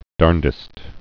(därndĭst)